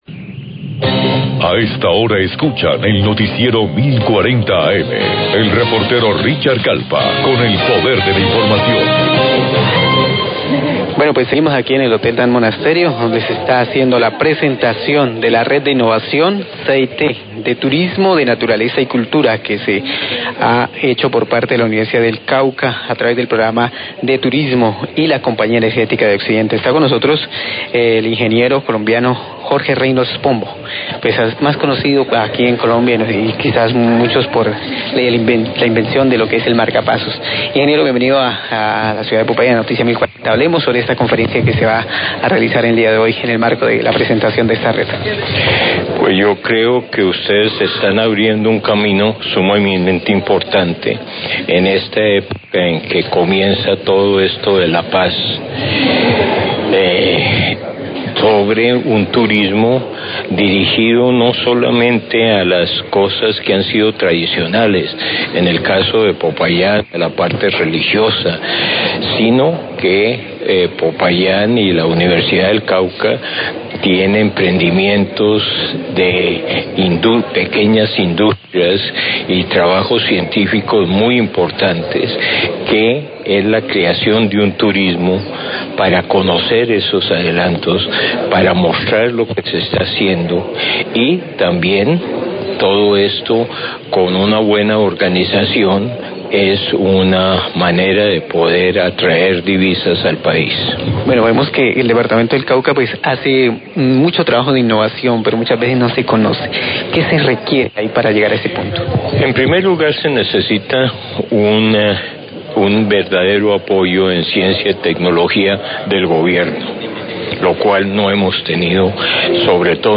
Radio
El científico Jorge Reynolds, inventor del marcapaso, habla de su conferencia que ofrecerá duarante el evento de lanzamiento de la Red CIT de Innovación de Turismo de Naturaleza y Cultura, el cual cuenta con el apoyo de la Compañía Energética de Occidente.